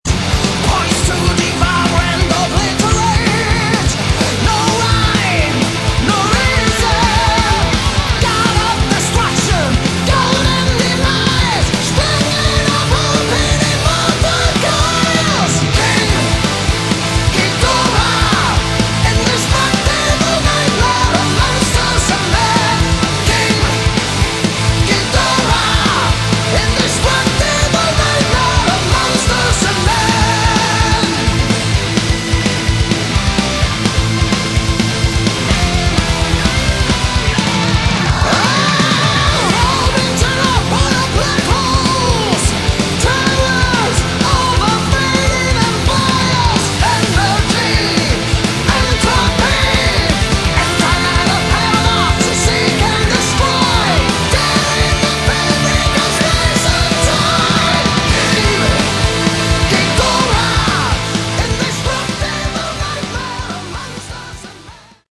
Category: Melodic Metal / Prog / Power Metal
guitars
keyboards
drums
bass
vocals
violin